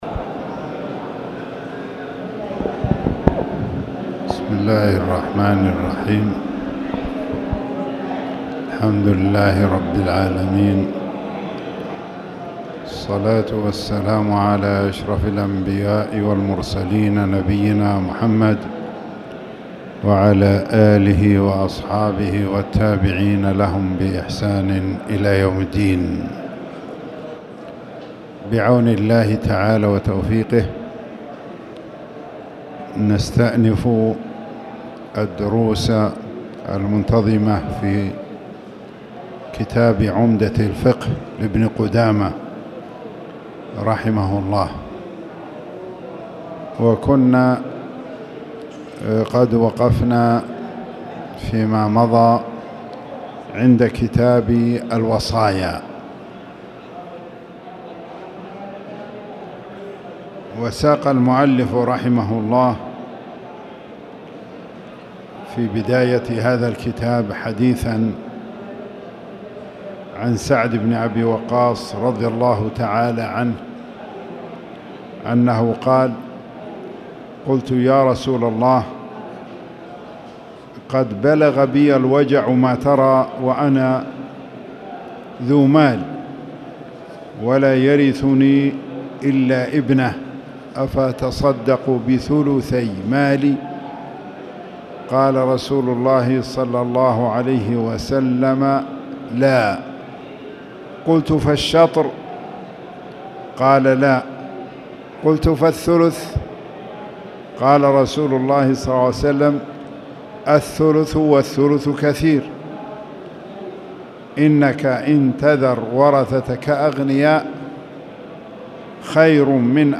تاريخ النشر ٢٩ ذو الحجة ١٤٣٧ هـ المكان: المسجد الحرام الشيخ